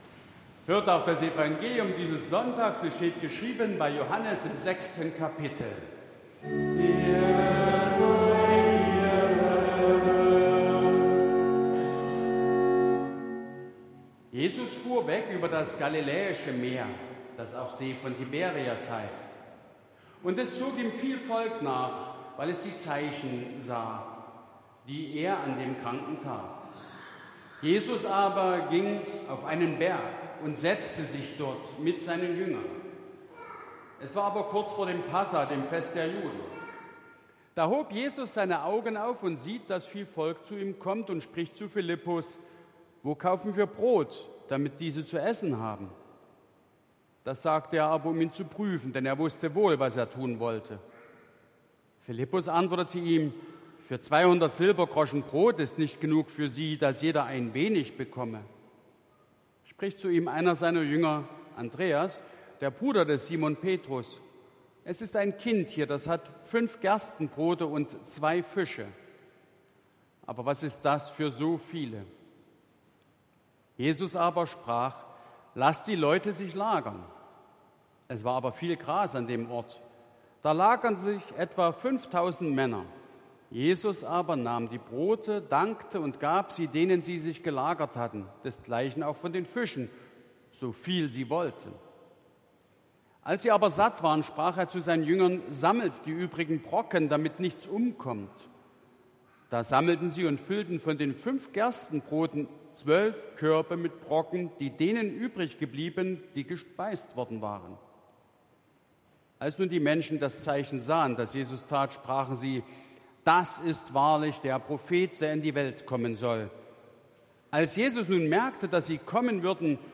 7. Lesung aus Johannes 6,1-15 Ev.-Luth.
Audiomitschnitt unseres Gottesdienstes vom Sonntag Lätare 2024